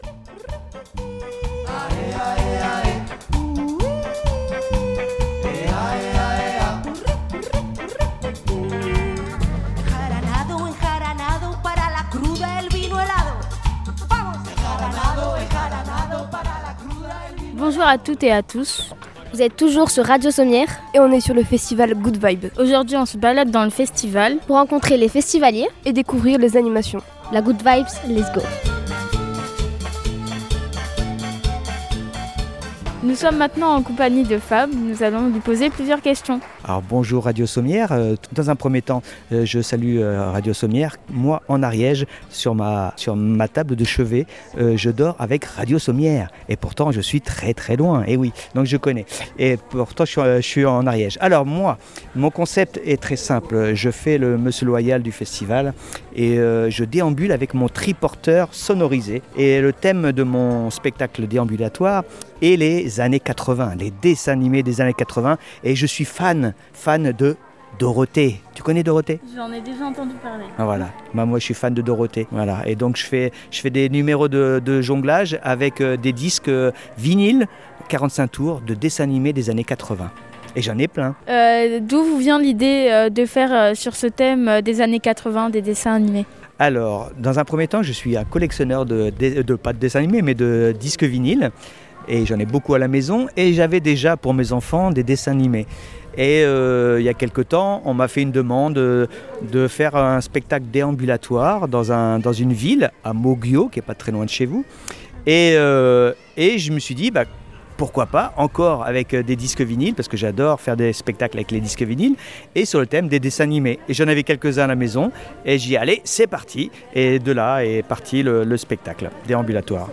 Reportage sur le Festival "Goodvibes"